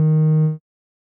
Techmino/media/sample/bass/19.ogg at d031dc3a82b43a0f700f15baaffff05abf2430e8
添加三个简单乐器采样包并加载（之后用于替换部分音效）